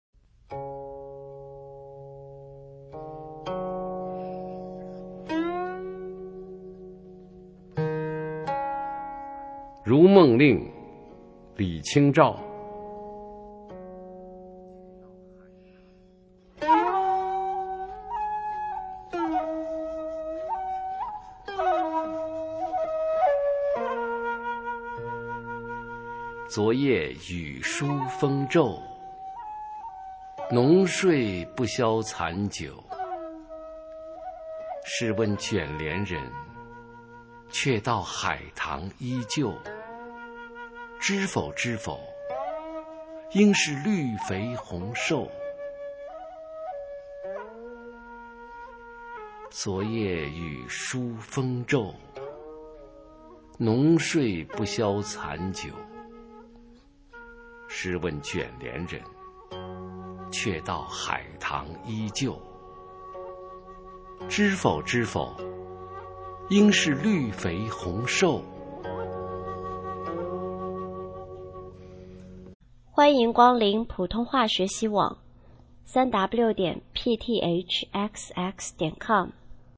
首页 视听 学说普通话 美声欣赏
普通话美声欣赏：如梦令-昨夜雨疏风骤